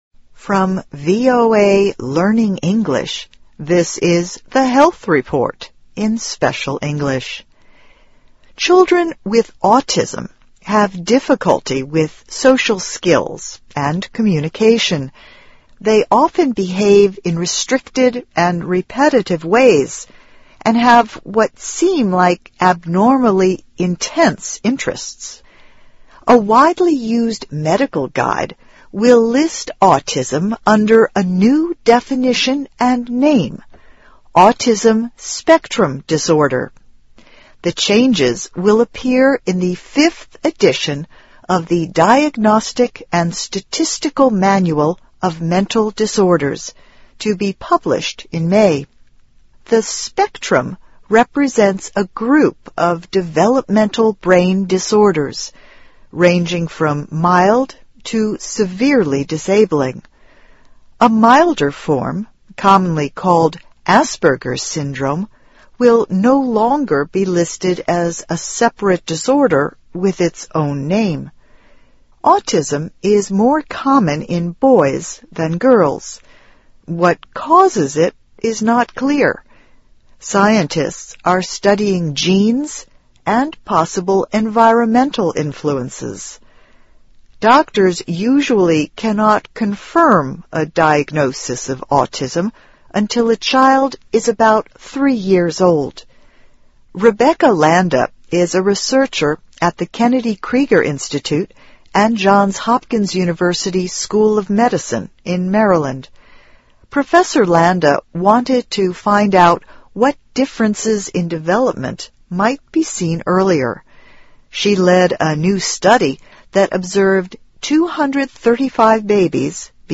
VOA慢速英语2013 健康报道 - 儿童自闭症的早期迹象 听力文件下载—在线英语听力室